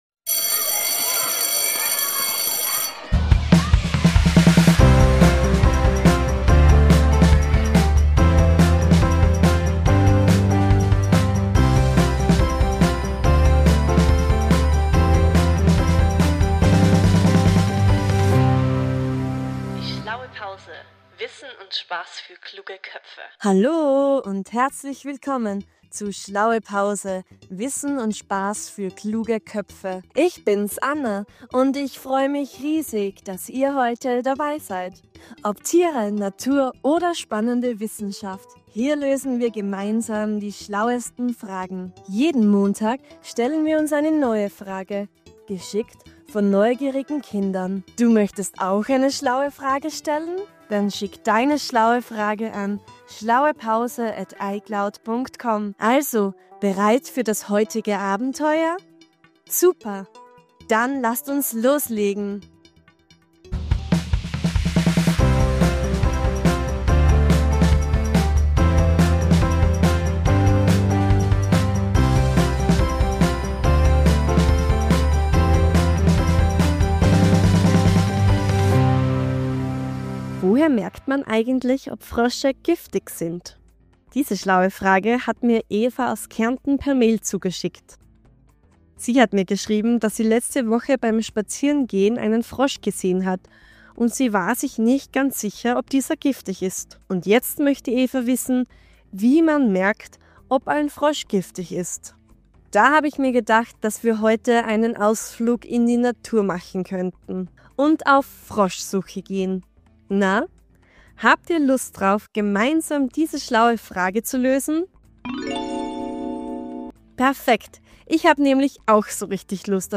In dieser Kinderpodcast-Folge erfahren wir mehr über Frösche.